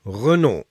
(Ütspraak üp franzk)?/i [ʀəˈno] es en franzk Autofirma. 1999 slööt Renault höm me Nissan en Mitsubishi töhop.
Renault_Ütspraak_franzk_001.ogg